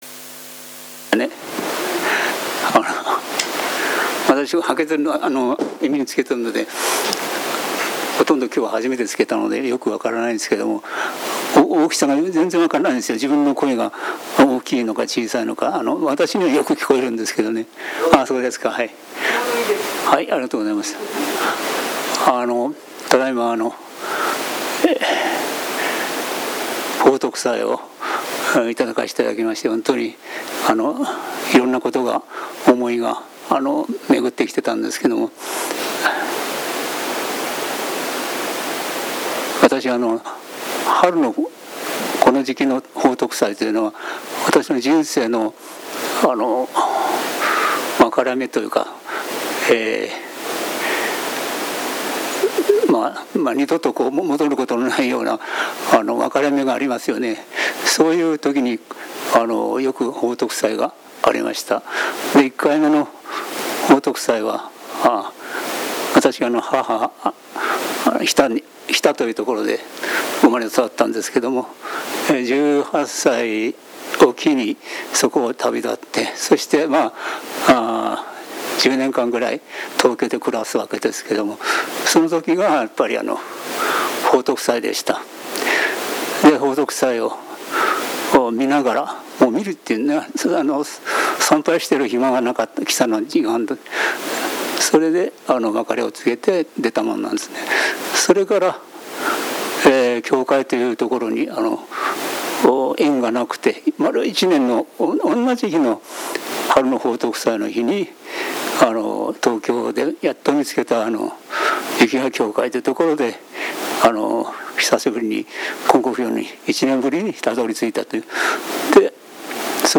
布教功労者報徳祭感話発表